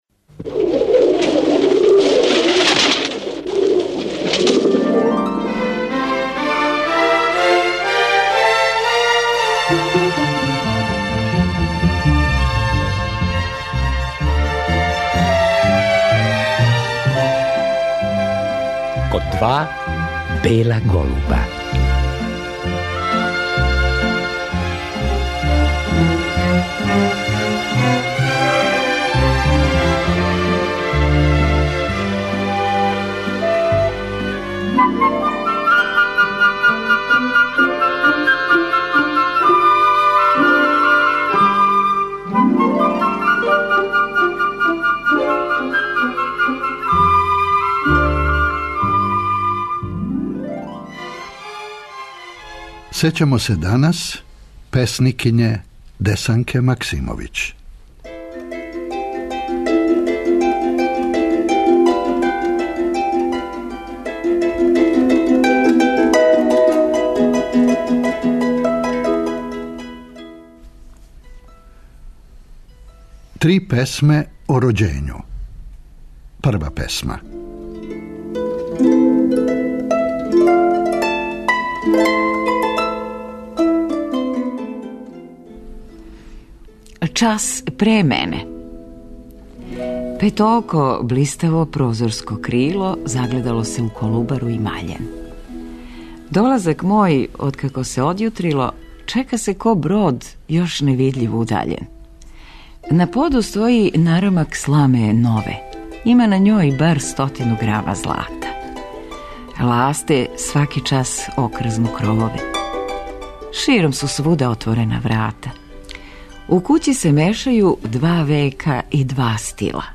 Чућемо снимак са књижевне вечери у Скадарлији, у кући Ђуре Јакшића из 1987. године и три мало познате Десанкине песме о њеном рођењу.